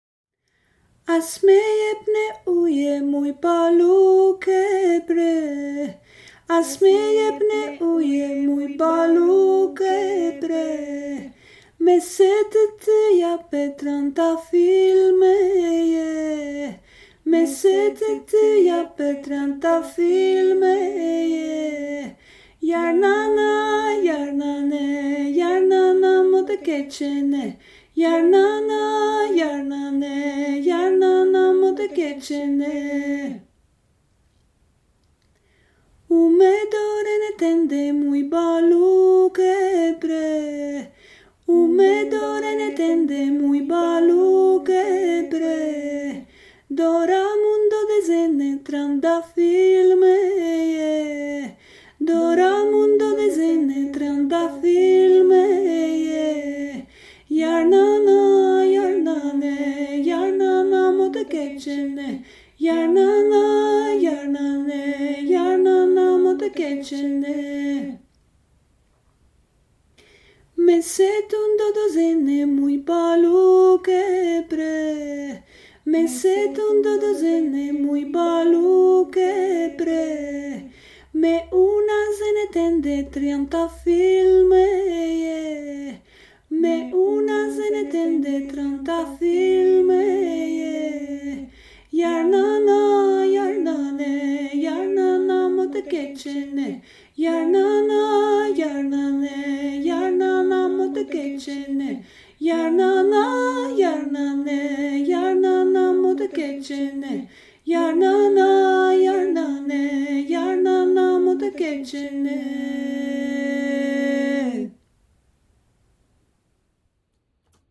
Albanian folk song
voice